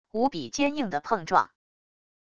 无比坚硬的碰撞wav音频